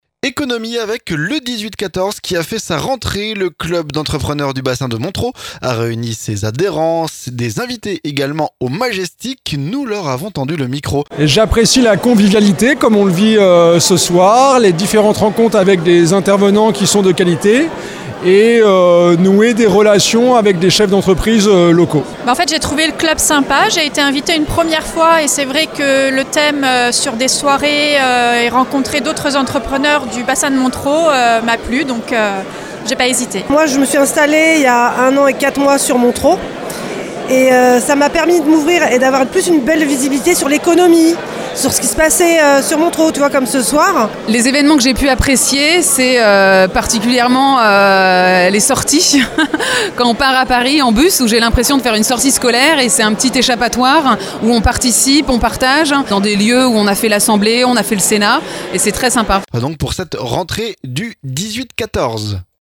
MONTEREAU - Le 18-14 fait sa rentrée, notre reportage
Le club d'entrepreneurs du bassin de Montereau, le 18-14, a fait sa rentrée. Les adhérents et des invités étaient réunis au Majestic. Micro tendu à ces derniers.